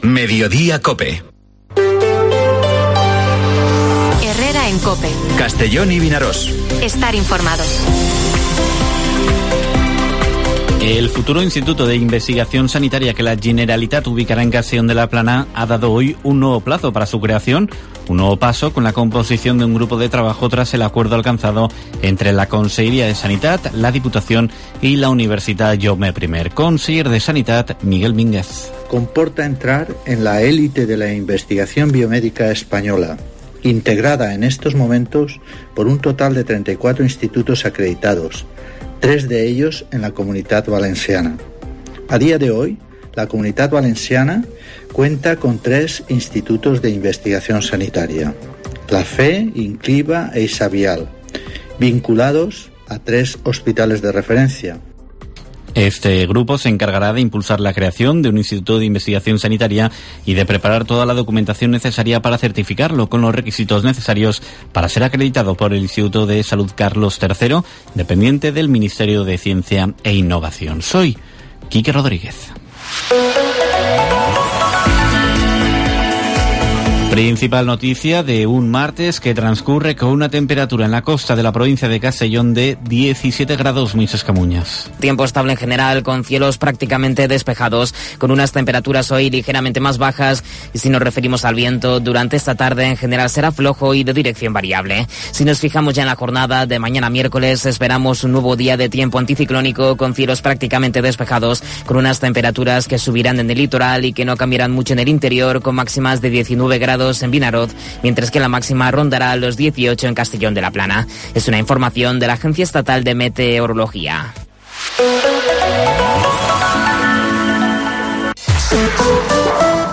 Informativo Mediodía COPE en la provincia de Castellón (10/01/2023)